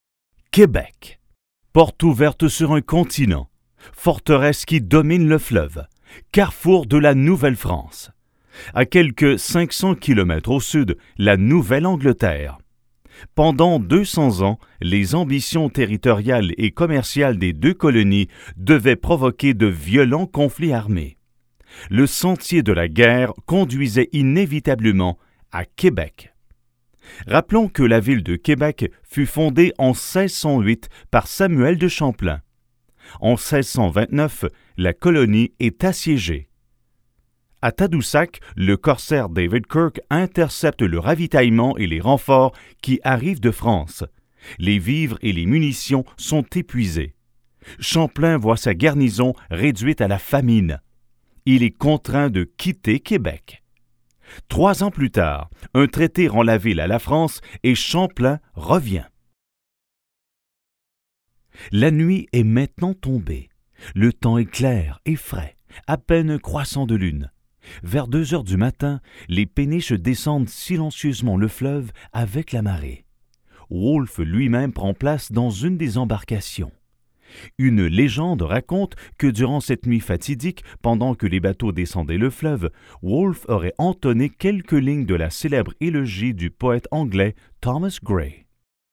Authentic french canadian male voice
Sprechprobe: Industrie (Muttersprache):